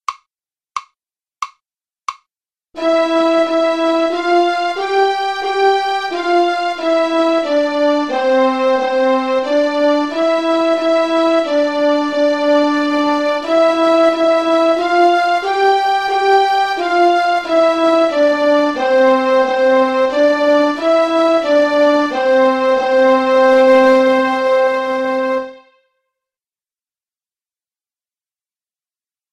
"Ode to Joy" melody track